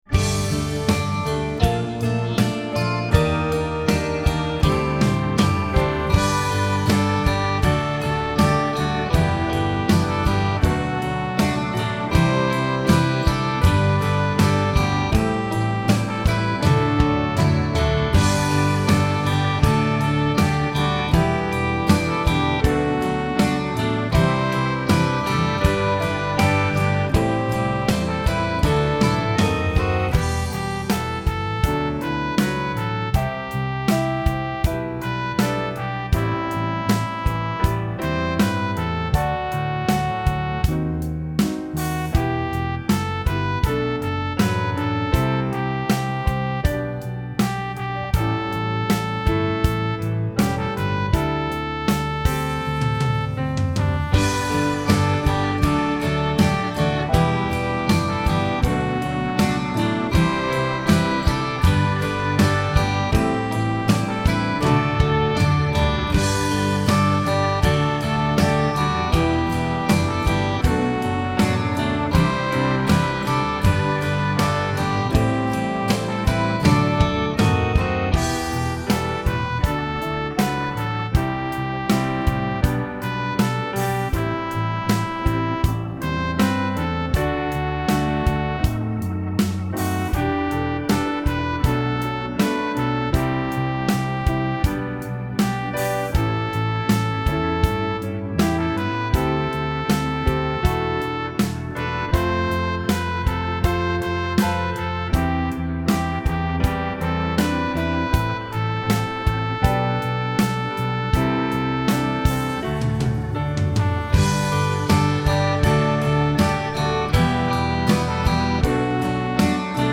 It’s a bit wishy washy actually.